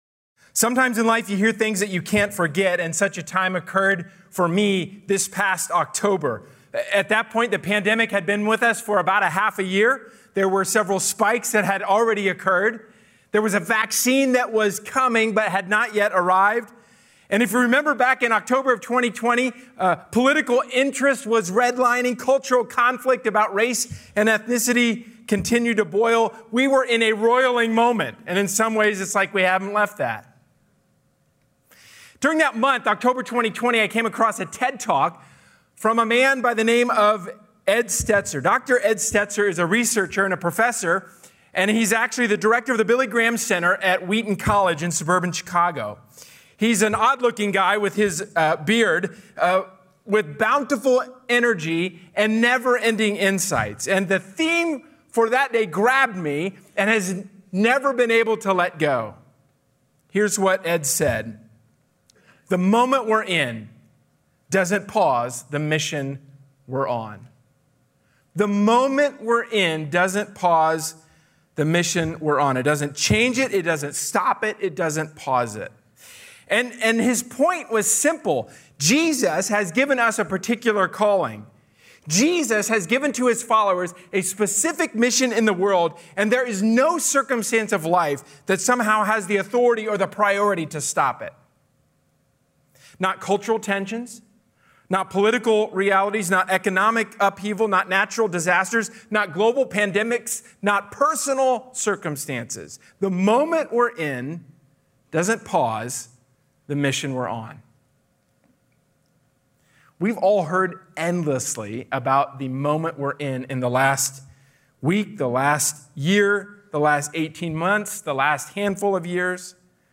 Sermon: "Vision Sunday 2021"